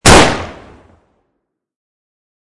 gunshot.mp3